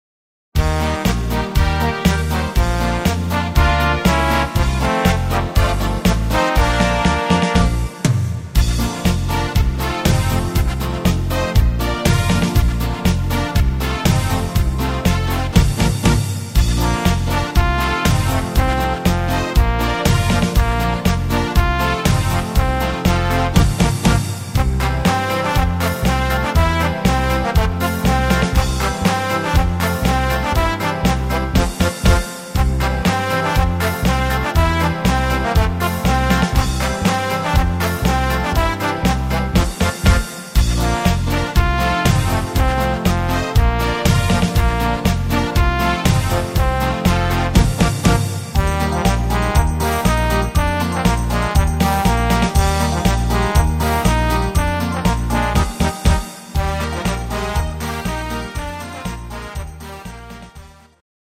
instr.Trompete